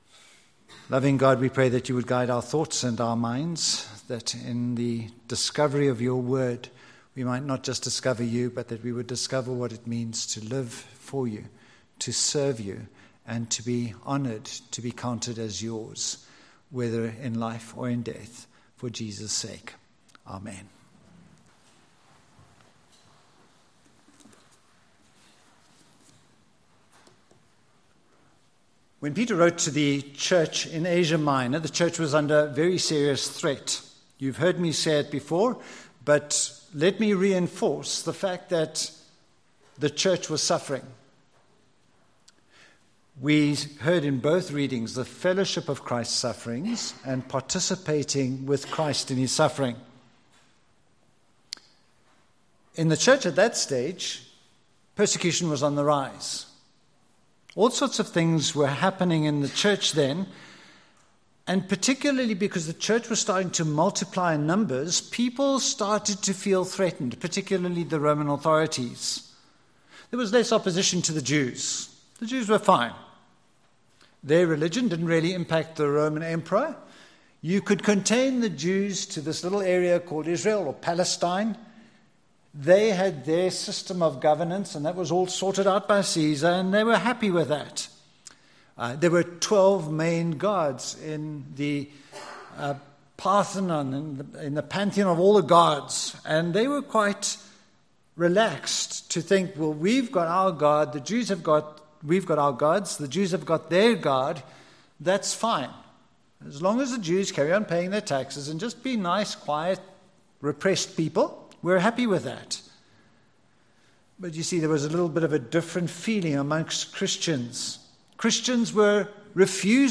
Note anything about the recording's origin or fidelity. Philippians 3:1-14 Service Type: Sunday Morning What are the “Sufferings of Christ”?